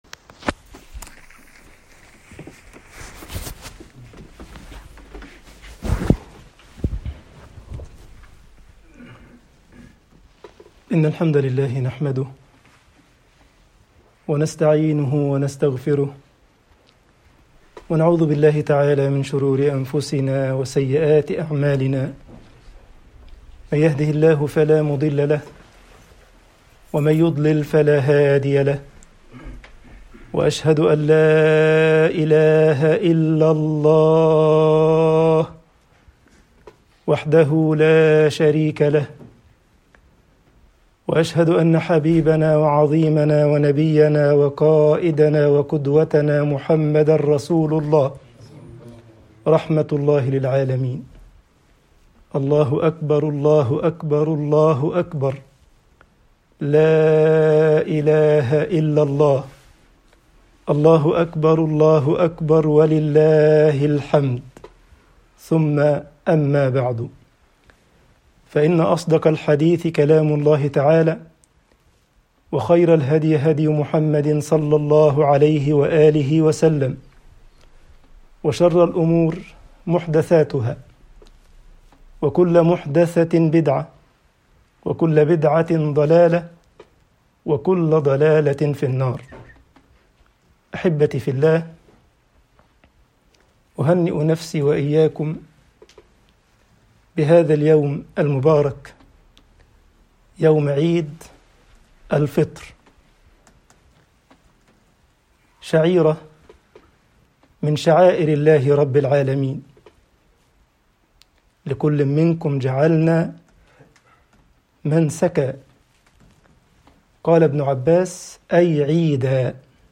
خطبة عيد الفطر 1442هـ العيد وتعظيم شعائر الله